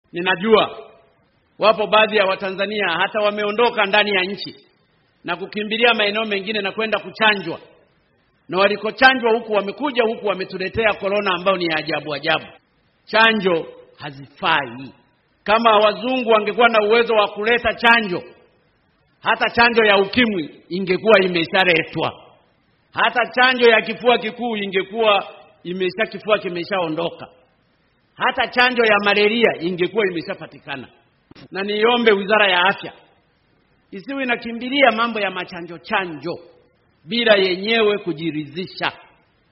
COVID-19 : Maoni ya Magufuli